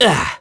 Esker-Vox_Damage_02.wav